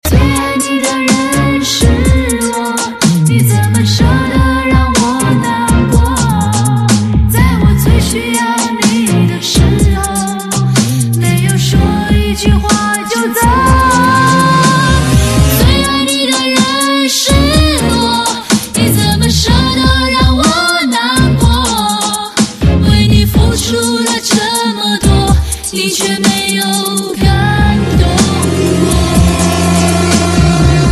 手机铃声